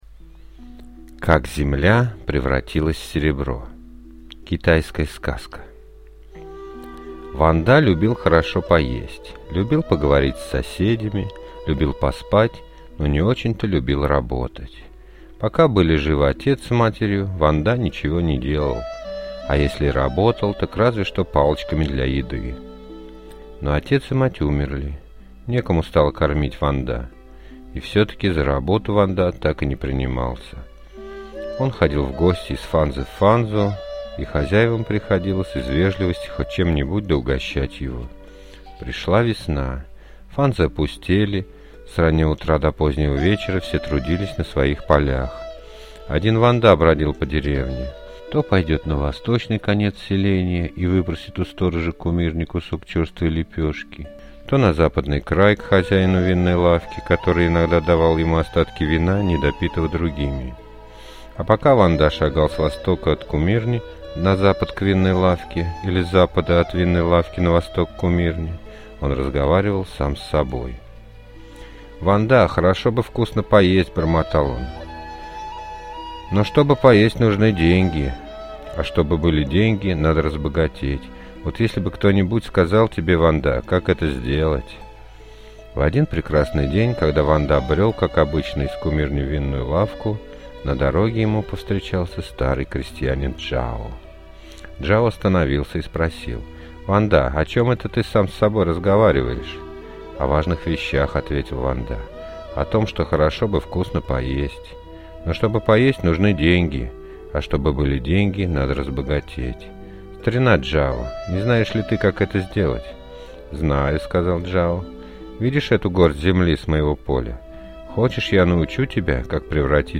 Как земля превратилась в серебро – китайская аудиосказка